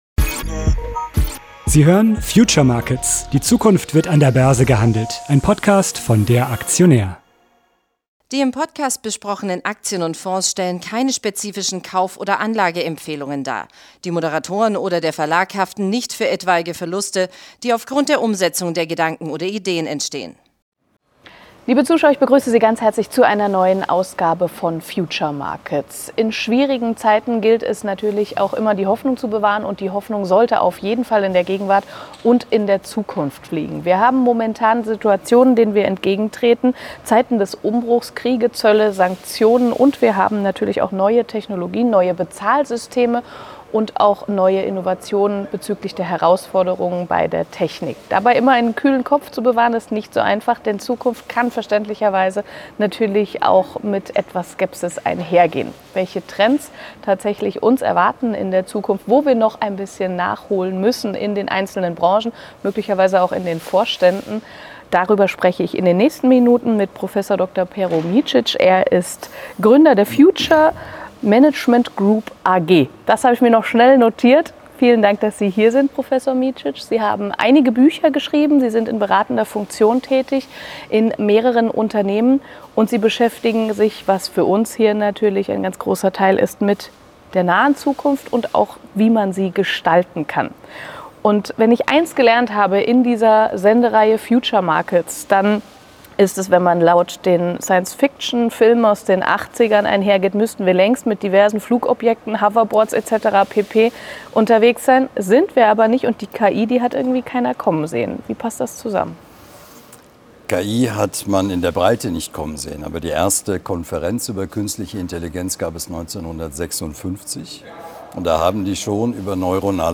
Das Gespräch wurde am 04.04.2025 an der Frankfurter Börse aufgezeichnet.